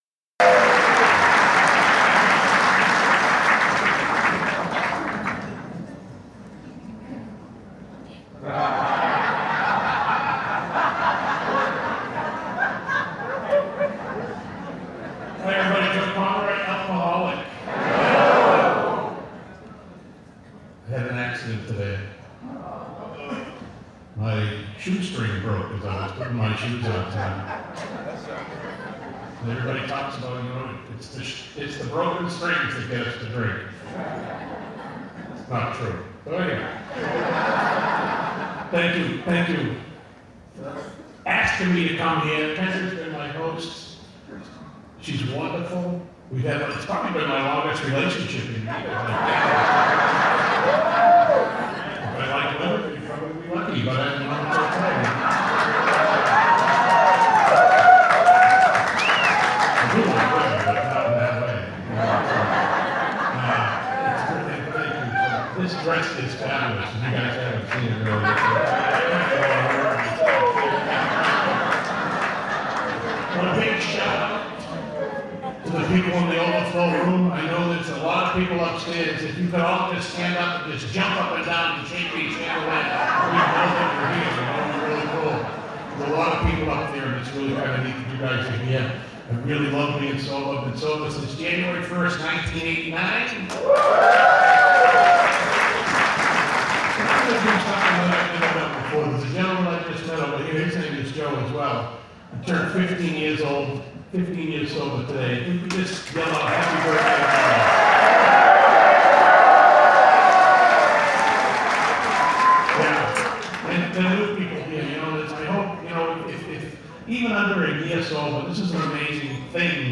47th Annual San Fernando Valley AA Convention